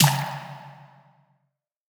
Snare (9).wav